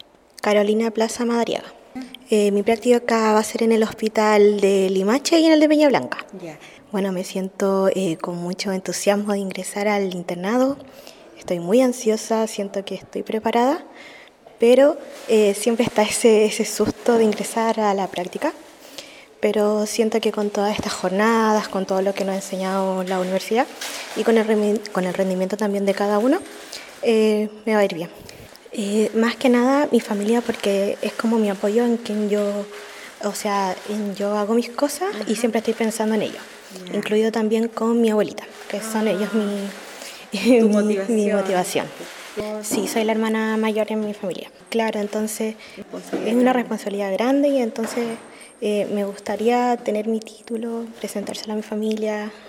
Compartimos con ustedes parte de lo que conversamos con algunos estudiantes, quienes desde su historia personal, evaluaron lo que significa para ellos, el inicio de sus prácticas profesionales, a fines de este mes.
Testimonios